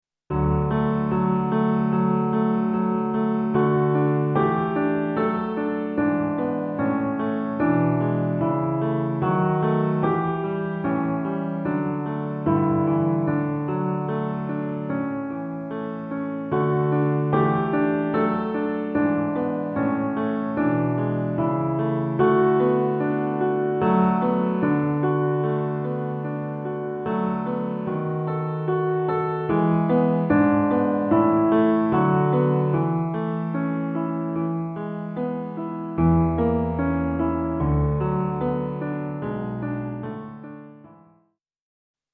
Home : Dwarsfluit :